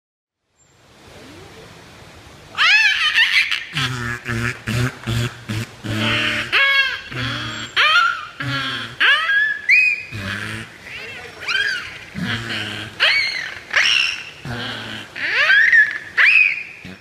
Звуки пения белухи в морских глубинах